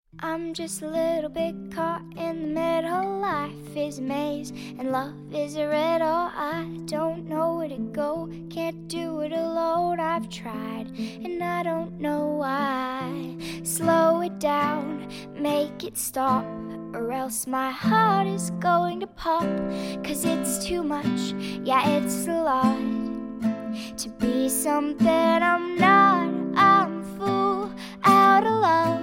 cute-little-girl_21223.mp3